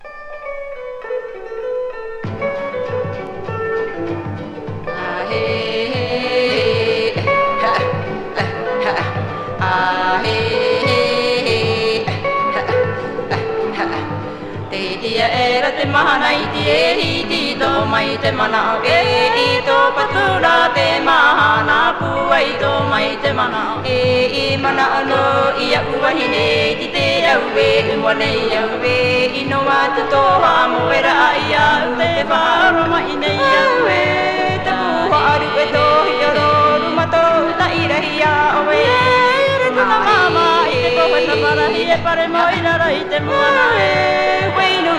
World, Field Recording, Tahiti　USA　12inchレコード　33rpm　Mono